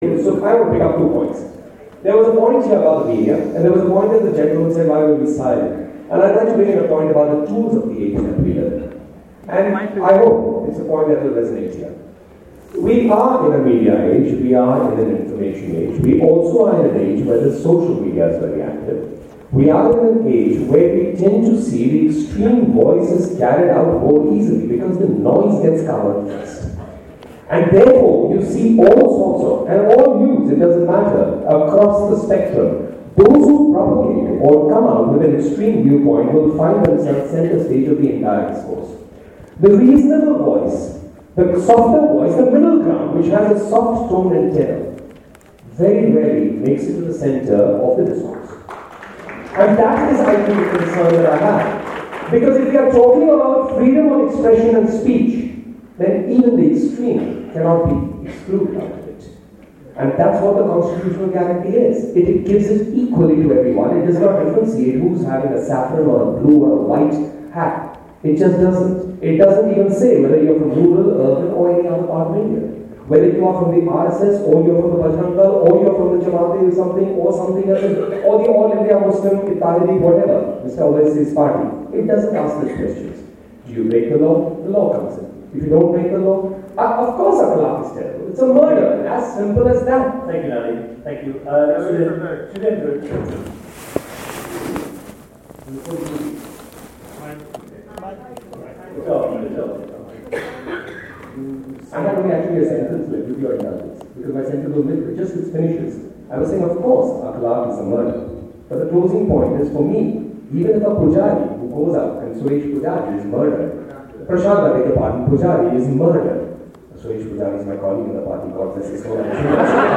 Tata LitLive full debate: It was at a debate on 'Freedom of expression is in imminent danger,' where actor Anupam Kher and Nalin Kohli of the BJP were speaking against the motion, facing off with celebrated writers Shobhaa De and Sudheendra Kulkarni who spoke for it.